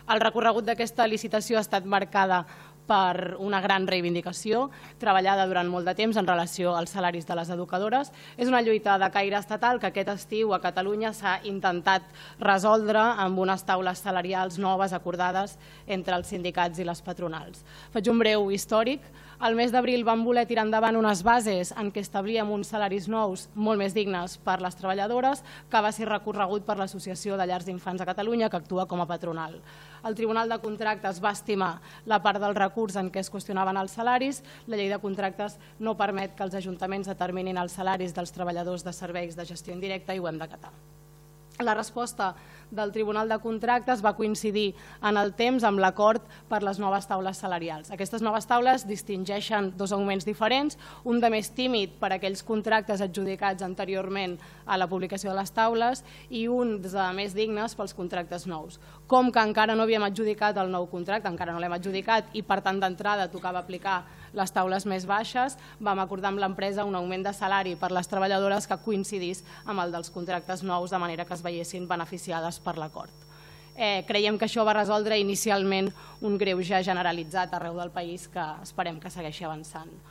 El ple d’aquest dimarts 14 de gener ha aprovat, amb els vots en contra d’ERC, PSC i el regidor no adscrit i l’abstenció de Junts per Catalunya Tiana i el PP; la nova licitació del servei de gestió de l’escola bressol municipal.
Tal com va explicar la regidora d’Educació, Eulàlia Serrat, en aquestes noves bases han hagut d’acatar la decisió del Tribunal de Contractes, que va donar la raó a la patronal perquè la llei “no permet que els ajuntaments determinin els salaris dels treballadors dels serveis de gestió indirecta”: